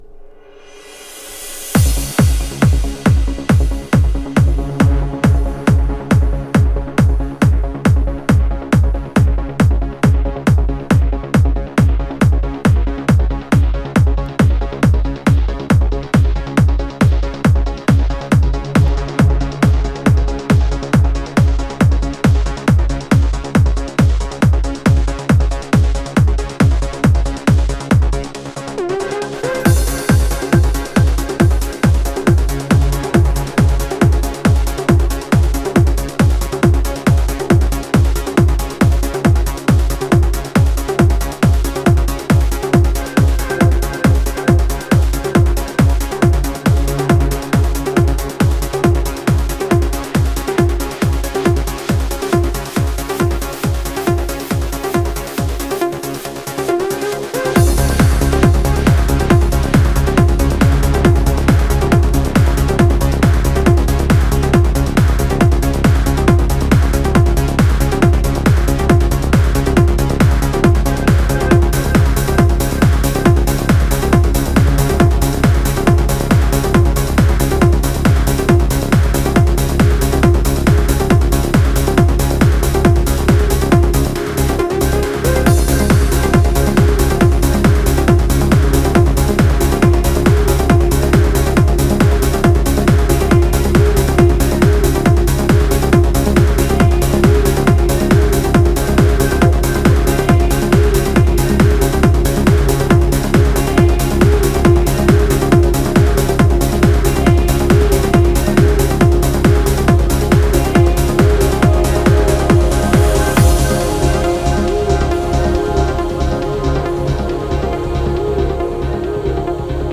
her vocal in this song sublime.